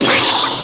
blow04.wav